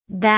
Audio of the phoneme for Commonscript letter 29 (pronounced by female).
Phoneme_(Commonscript)_(Accent_0)_(29)_(Female).mp3